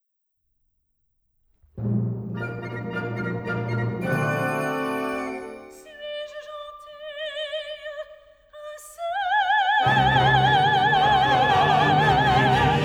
As you can see, the solo and non-solo sections look quite different.  The solo music is much more sparse because there is only one pitch at any given time.  The frequencies that register are the pitch, twice the pitch, three times the pitch, etc.  When the orchestra is playing, in contrast, there are many different pitches.